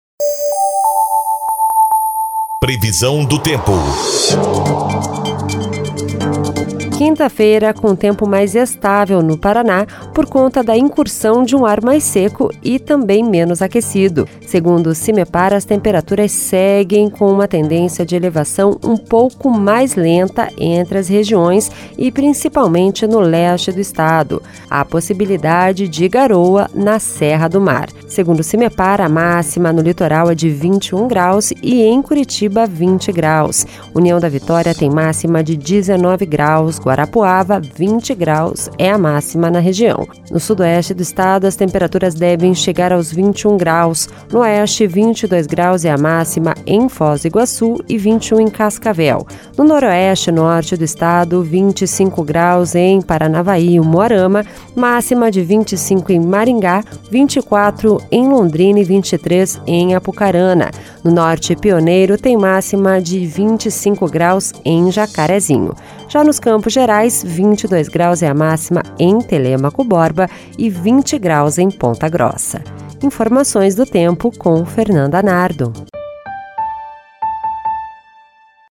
Previsão do Tempo (12/05)